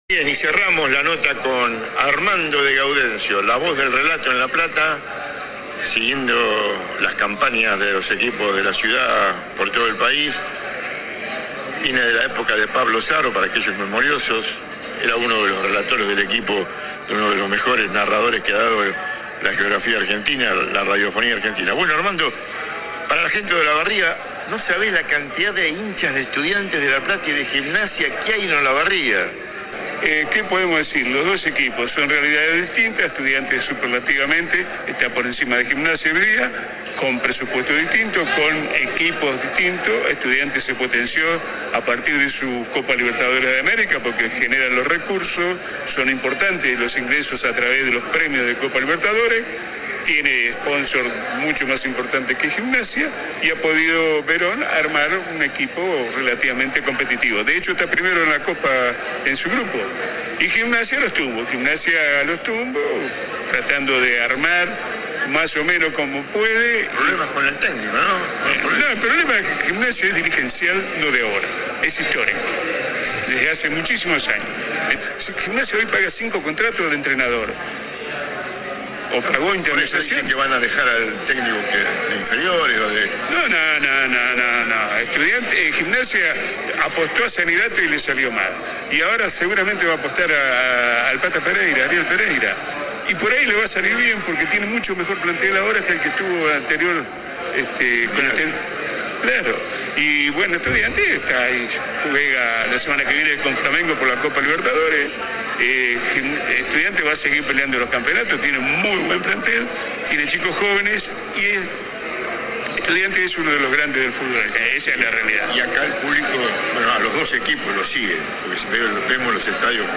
AUDIO DE LA ENTREVISTA ( en dos bloques )